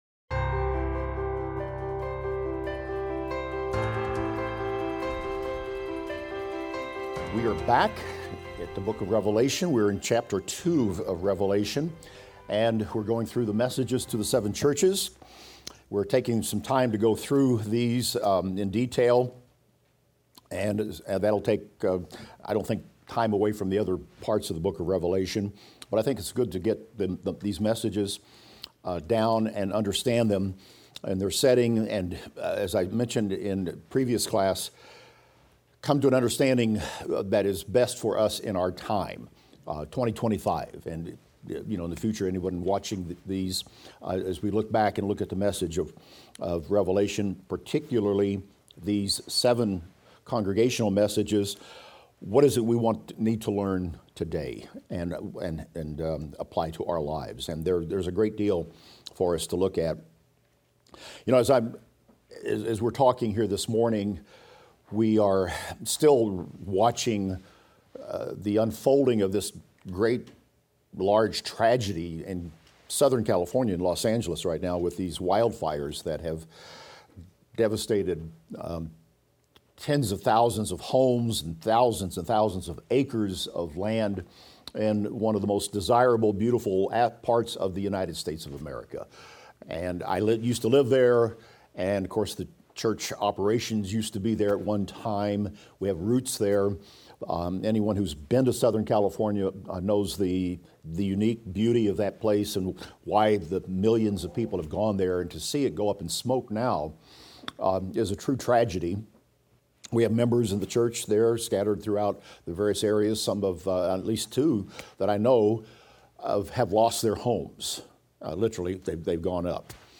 Revelation - Lecture 29 - audio.mp3